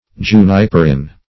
juniperin - definition of juniperin - synonyms, pronunciation, spelling from Free Dictionary
Search Result for " juniperin" : The Collaborative International Dictionary of English v.0.48: Juniperin \Ju"ni*per*in\, n. (Chem.) A yellow amorphous substance extracted from juniper berries.